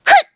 Taily-Vox_Attack2.wav